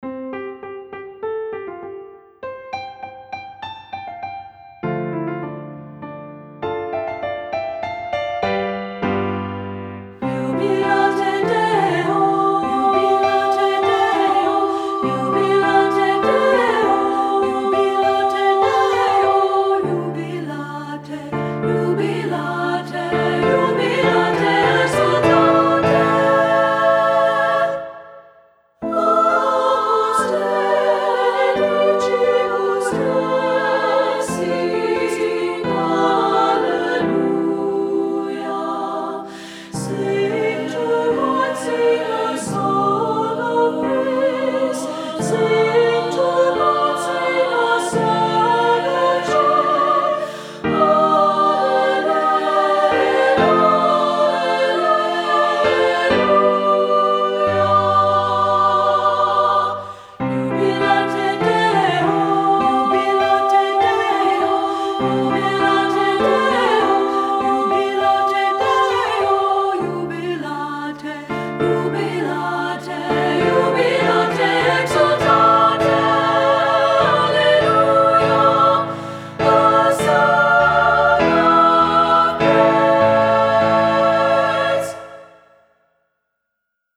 Voicing: SSA